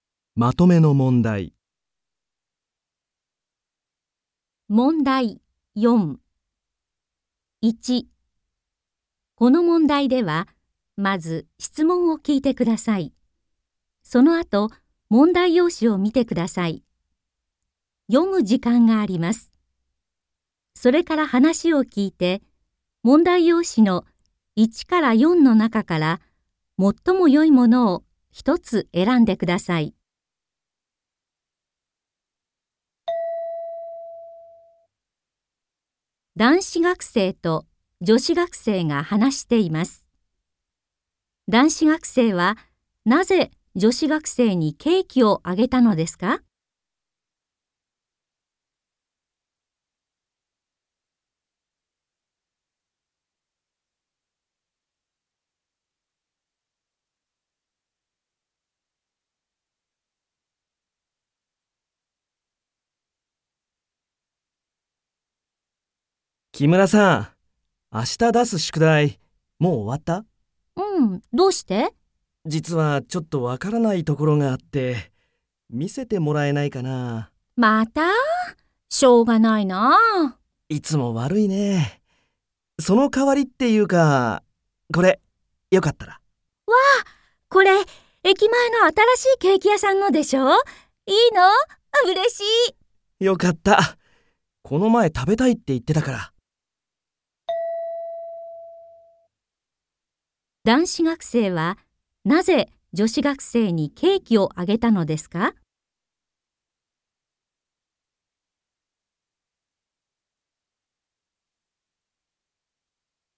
問題4 ［聴解］